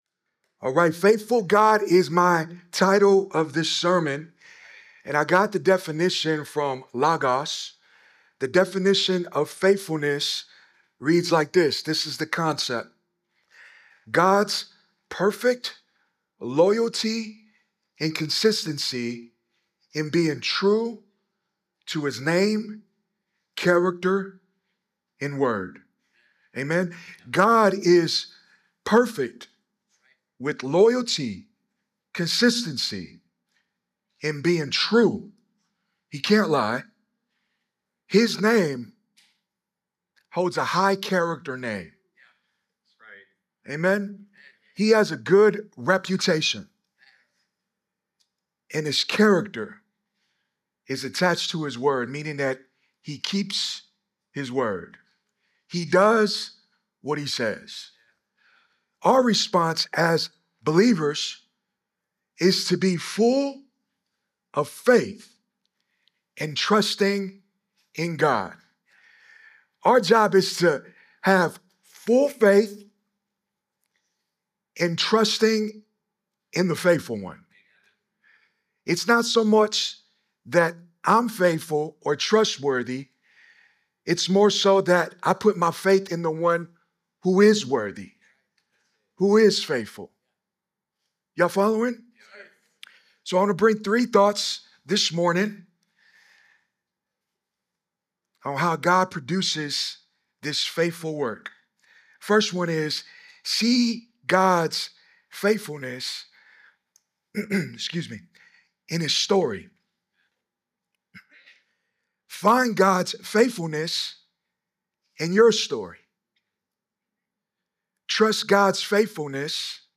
Sermons podcast of Walk Church in Las Vegas, NV